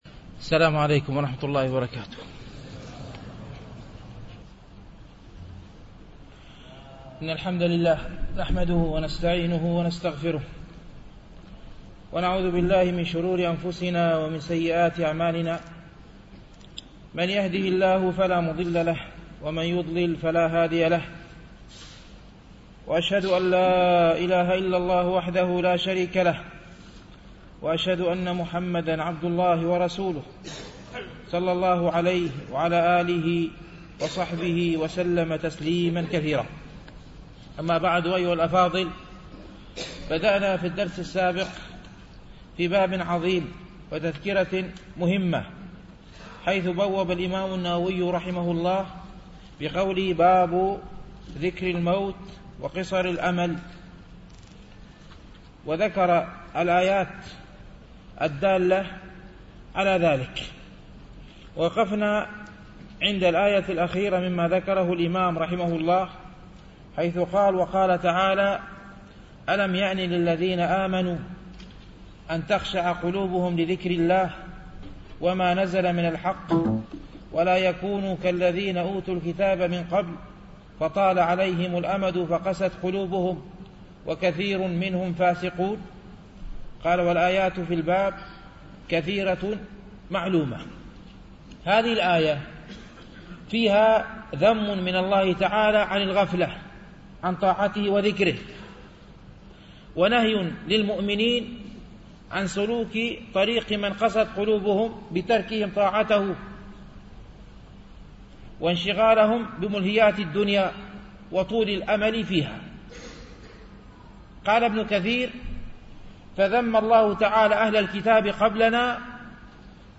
شرح رياض الصالحين - الدرس الثامن والخمسون بعد المئة